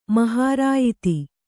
♪ mahārāyiti